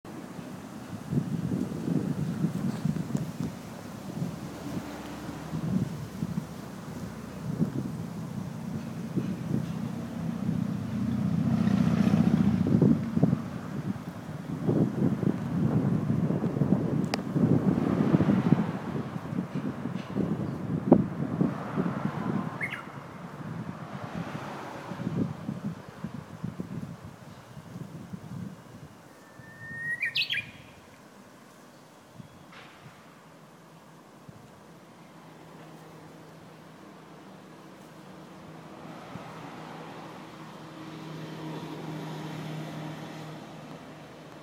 うぐいす！
ほーほけきょ！